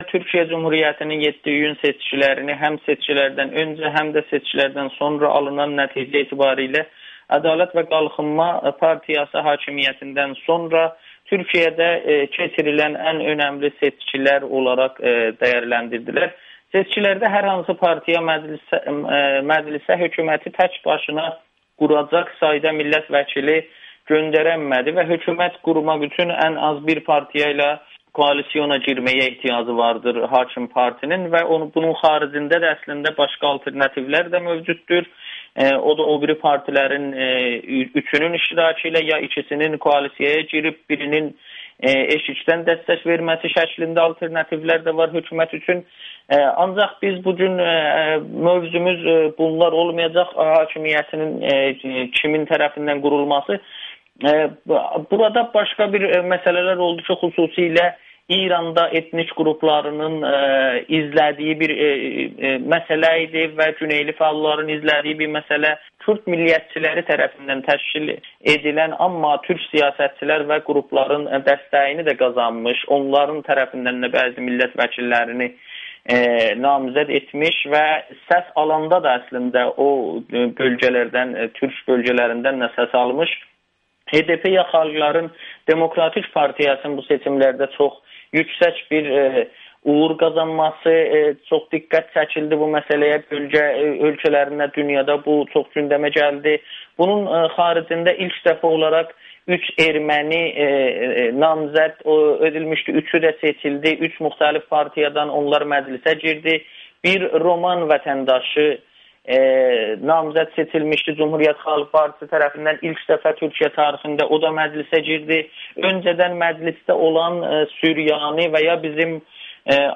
Amerikanın Səsi ilə söhbətdə